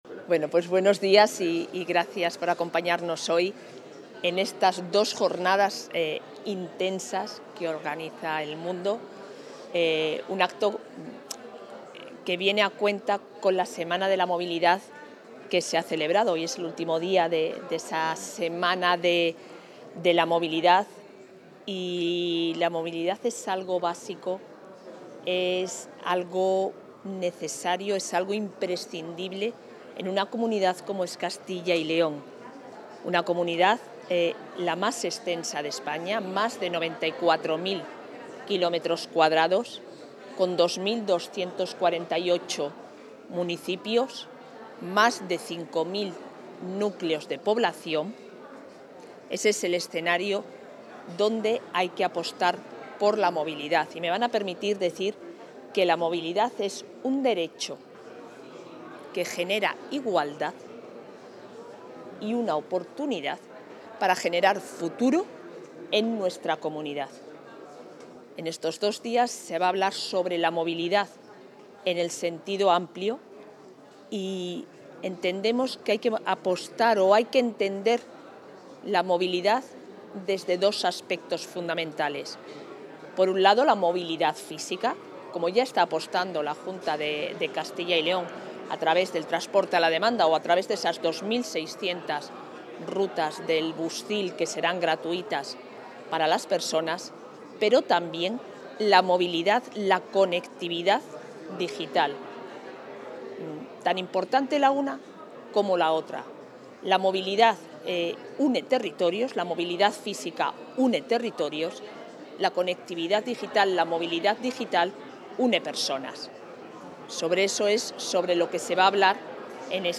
Intervención de la vicepresidenta de la Junta.